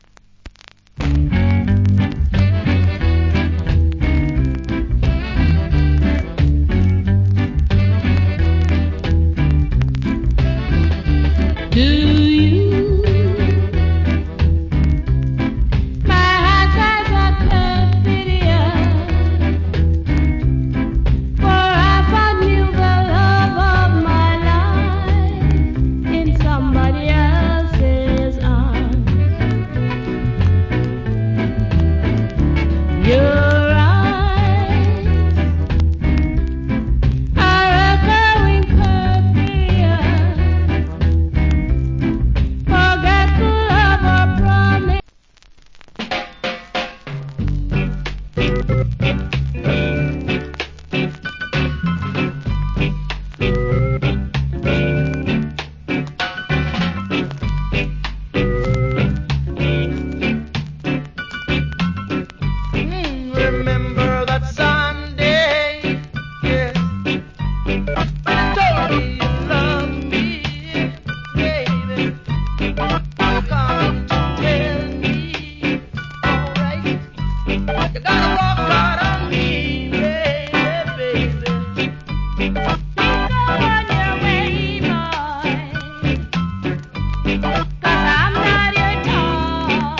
Sweet Rock Steady Vocal.